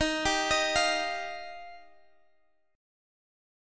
Listen to Ebadd9 strummed